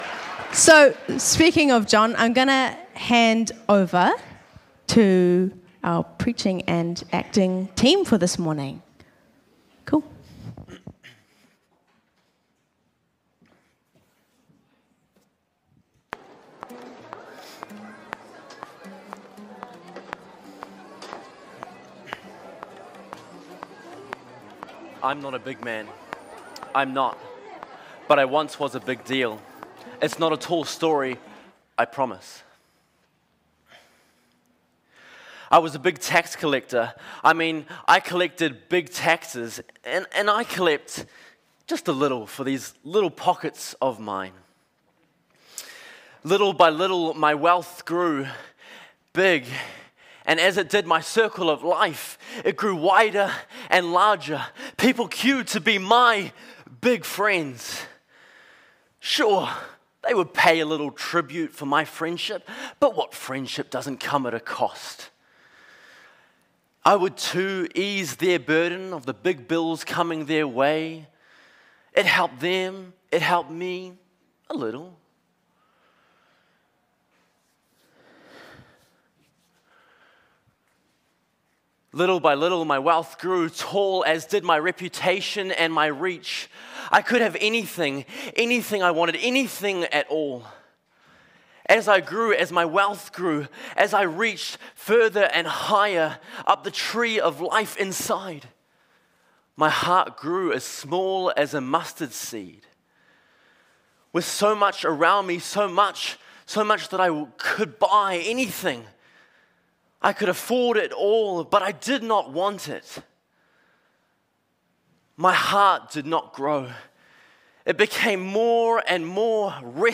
Sermons | Titirangi Baptist Church
As we bring these stories to life through the synergy of preaching and dramatic performance, we pray that it stirs a desire in all of us to extend the life-changing gospel of Jesus to those around us, fostering a community rooted in compassion and action. Today we are looking at Luke 19:1-10 where Jesus encounters Zacchaeus, a chief tax collector.